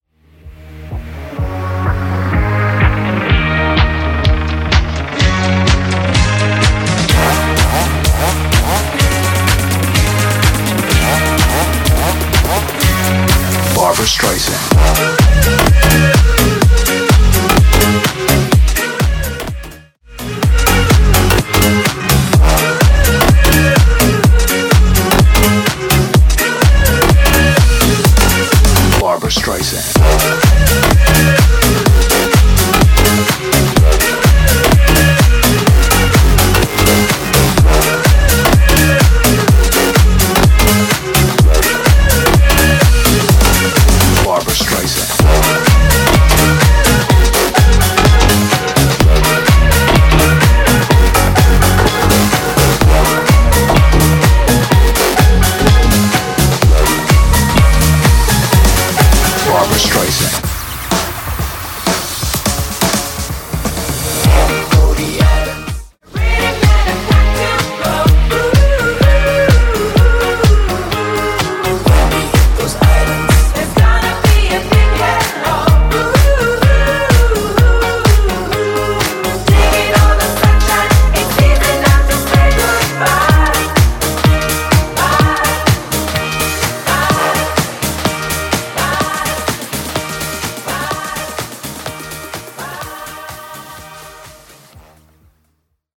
BPM: 126 Time